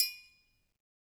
Triangle6-HitFM_v1_rr2_Sum.wav